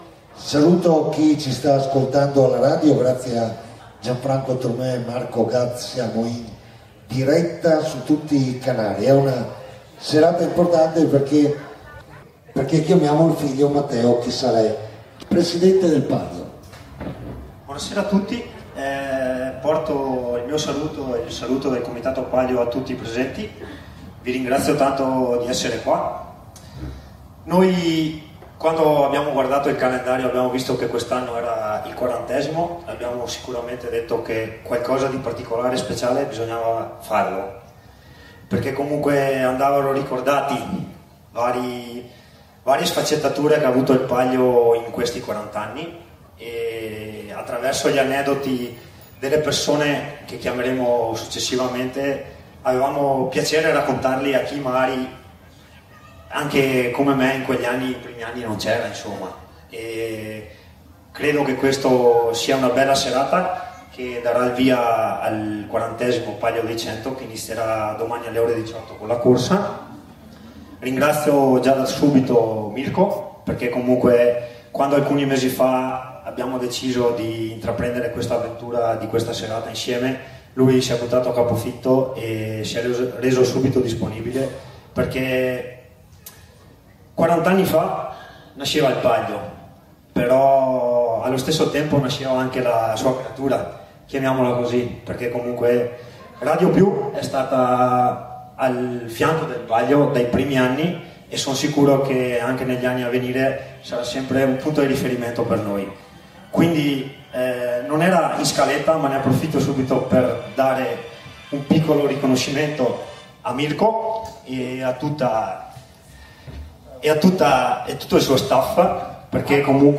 SERATA SUL BROI DI AGORDO